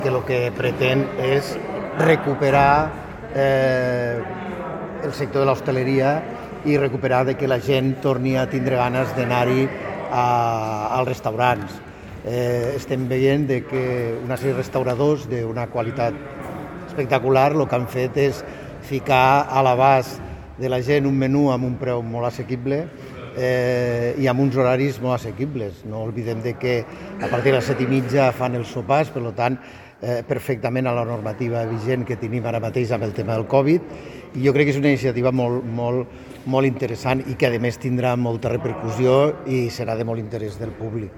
tall-de-veu-del-tinent-dalcalde-paco-cerda-amb-motiu-de-la-campanya-dels-xefs-lleidatans